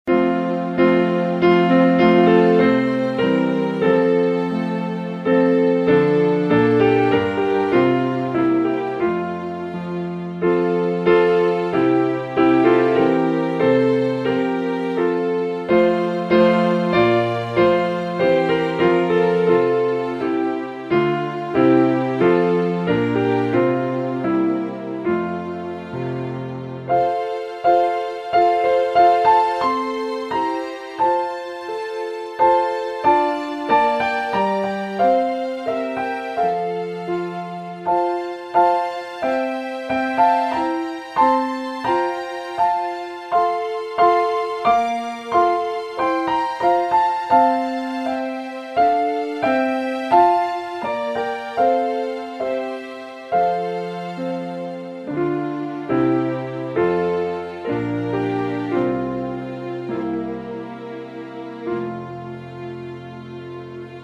AWR - Instrumental Music Podcast - Hymn, Sing | Free Listening on Podbean App